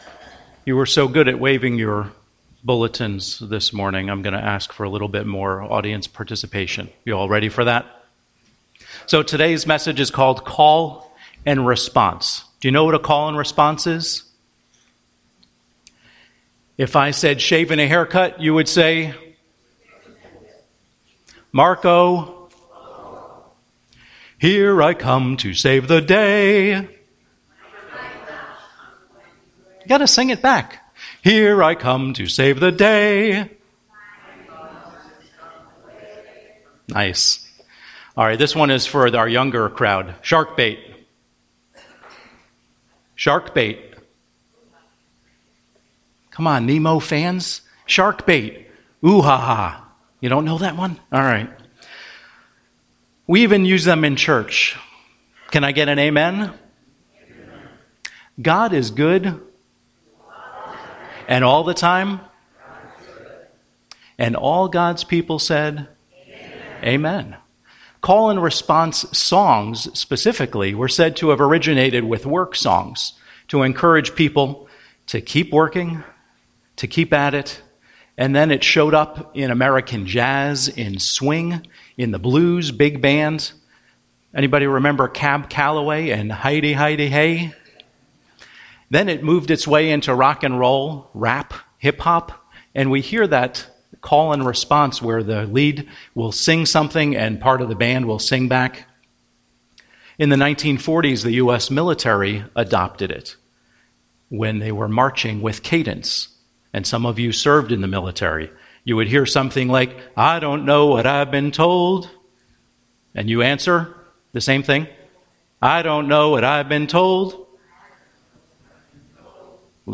Home › Sermons › Call & Response